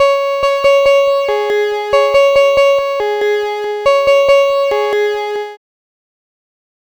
Cheese Lix Synth 140-C#.wav